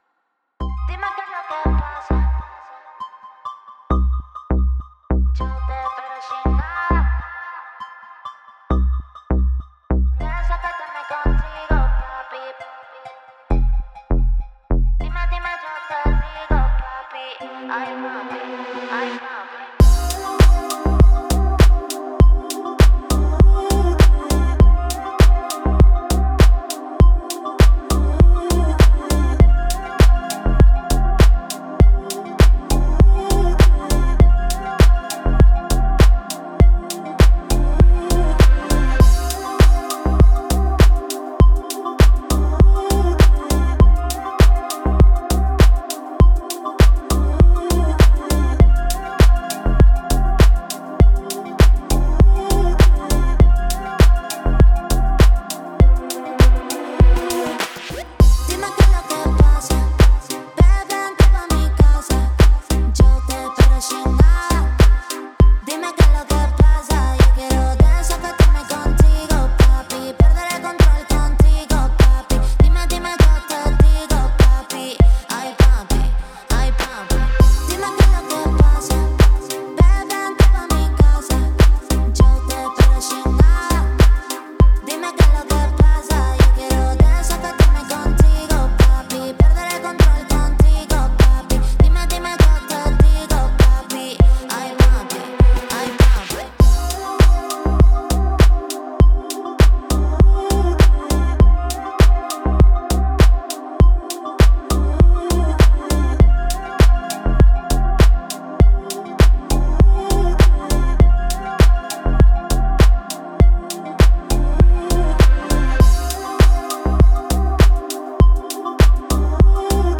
Жанр: Танцевальная